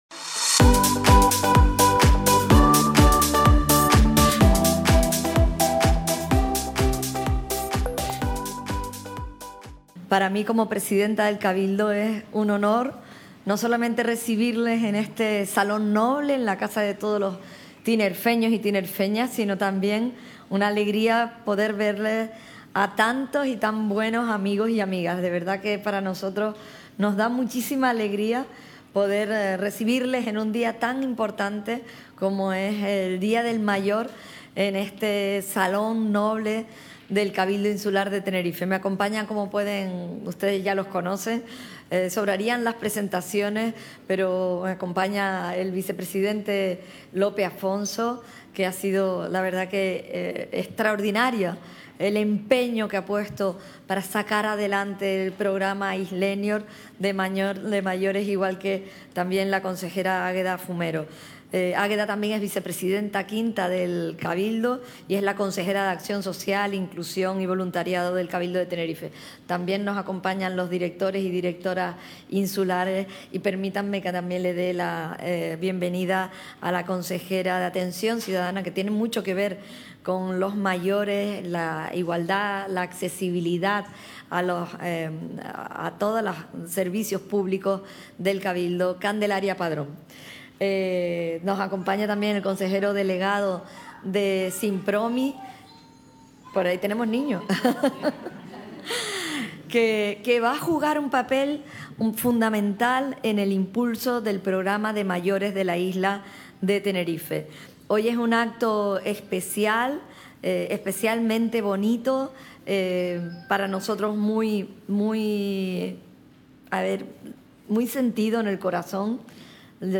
El Cabildo de Tenerife conmemoró hoy (martes 1) el Día Internacional de las Personas Mayores, con un acto que congregó a casi 100 mayores procedentes de municipios de toda la isla.